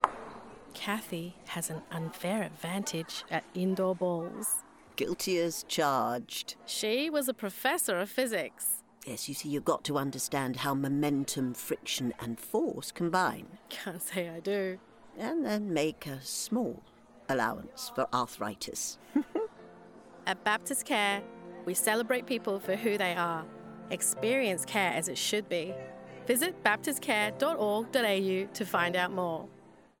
Female
I am a Sydney Australia based singer voice over artist with a natural Australian accent.
My voice has been described as friendly, trustworthy, convincing, authoritative, authentic, enthusiastic, enticing, cut from a different cloth with a sassy certainty by my clients and peers.
Radio Commercials